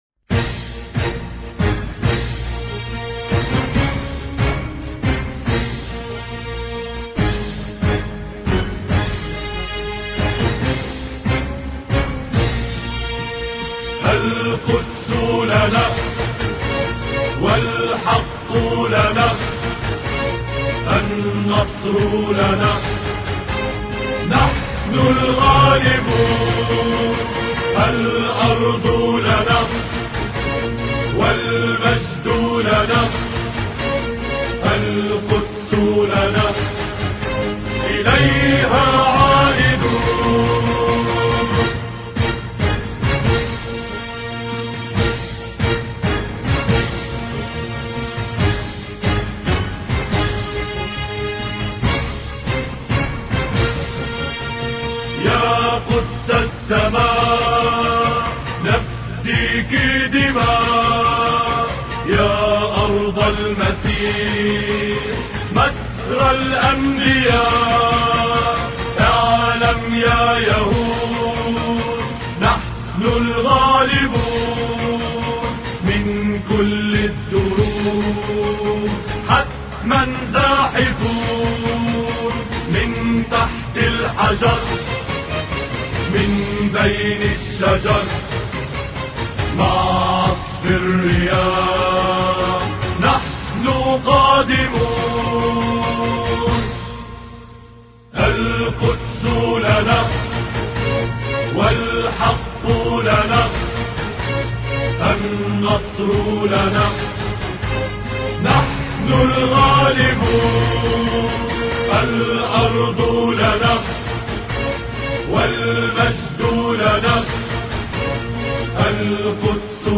الارض لنا الإثنين 23 يونيو 2008 - 00:00 بتوقيت طهران تنزيل الحماسية شاركوا هذا الخبر مع أصدقائكم ذات صلة الاقصى شد الرحلة أيها السائل عني من أنا..